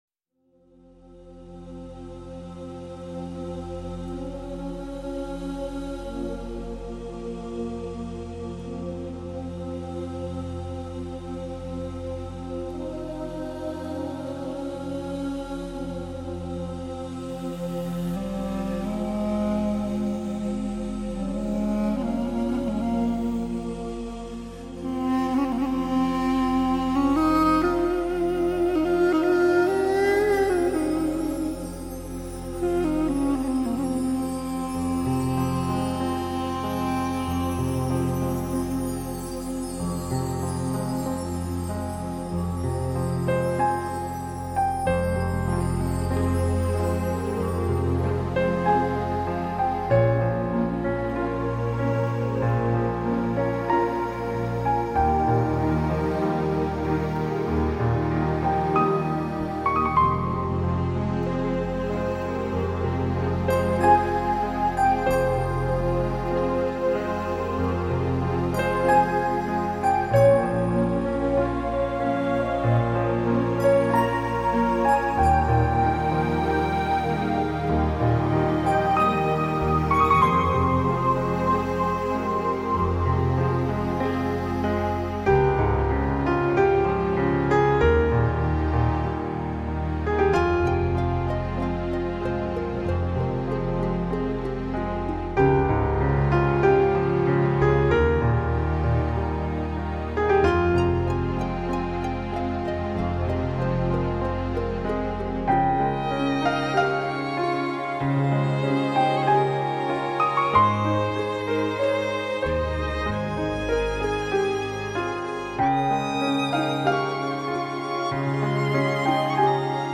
А в музыке... вначале - дудук...